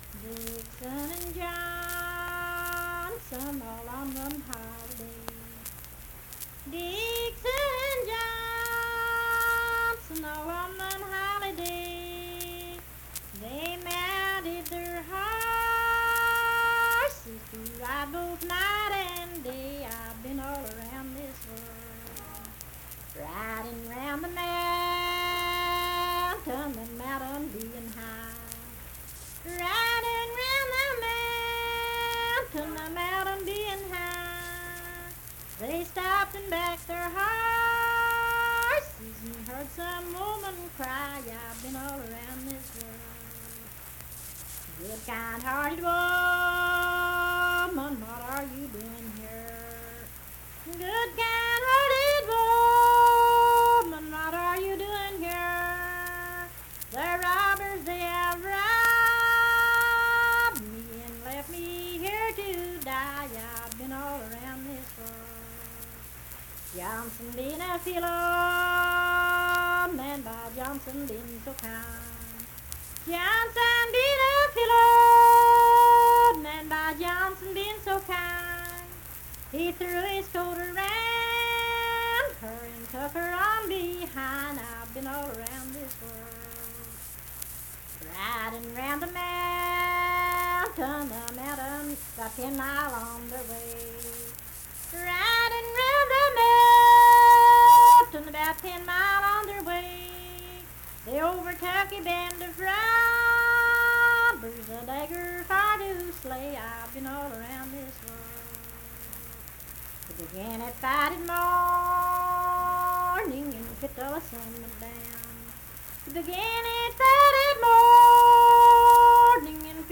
Unaccompanied vocal music
Verse-refrain, 9(4w/R).
Ballads, Folk music--West Virginia
Voice (sung)